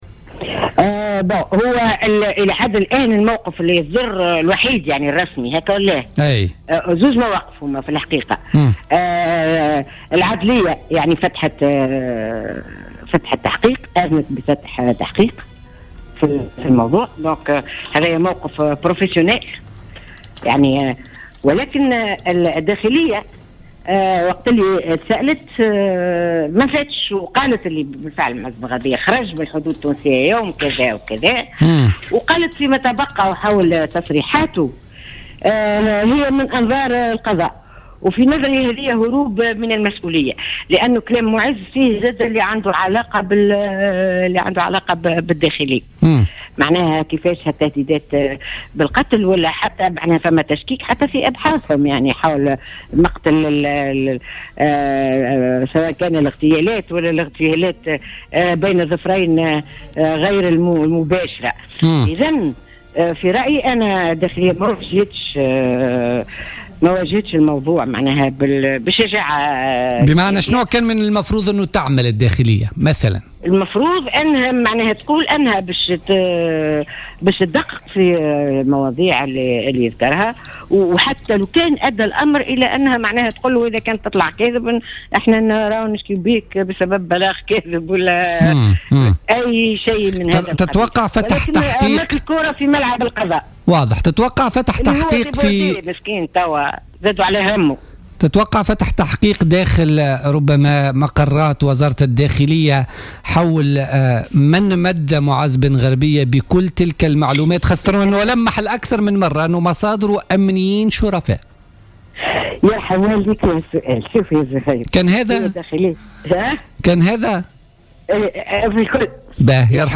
وأضافت أم زياد في مداخلة لها اليوم في برنامج "بوليتيكا" أن الداخلية تهربت من المسؤولية ورمت بالكرة في مرمى القضاء،حيث قالت في بلاغ لها أمس إنها أحالت الفيديو المذكور في قرص مضغوط مصحوبا بمكتوب في الغرض على النيابة العمومية.